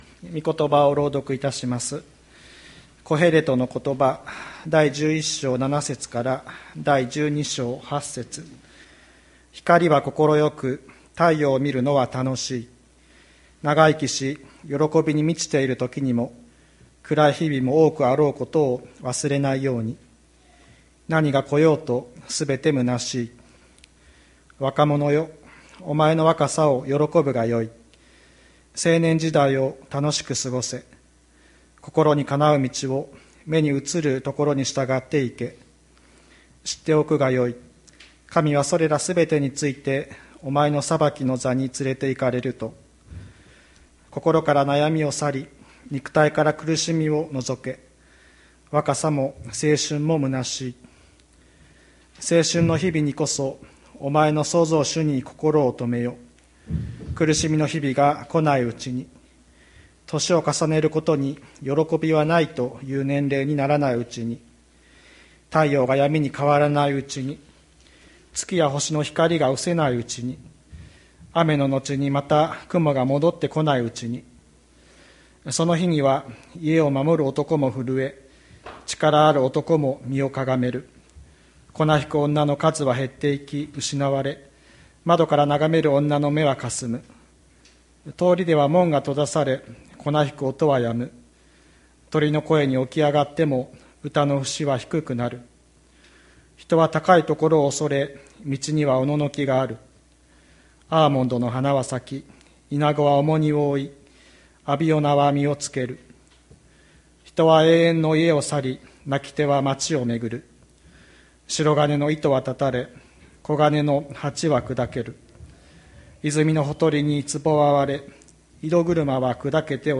2021年04月18日朝の礼拝「青春の日々にこそ」吹田市千里山のキリスト教会
千里山教会 2021年04月18日の礼拝メッセージ。